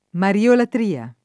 mariolatria [ mariolatr & a ]